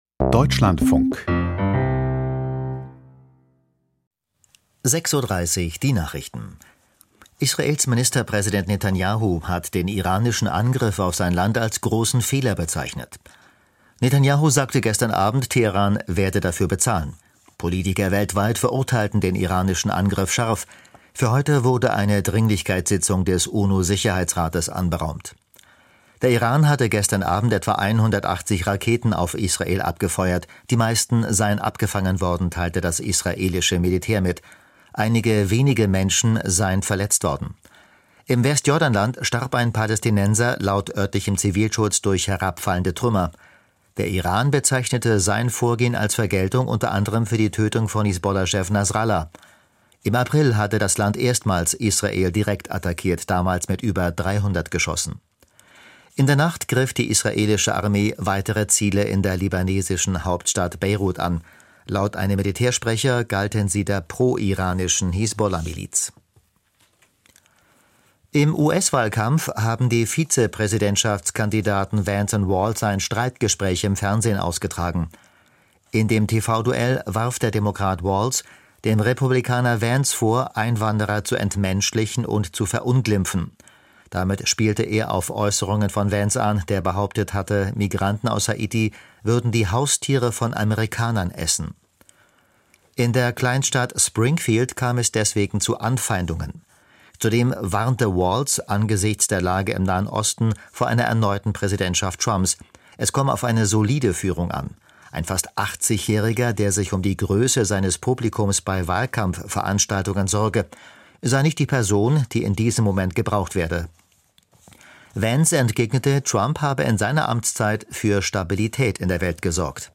Die Deutschlandfunk-Nachrichten vom 02.10.2024, 06:30 Uhr